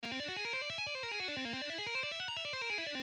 Lesson 3: Tapping an Exotic Lick
Original Speed:
Exercise-3-An-exotic-licks.mp3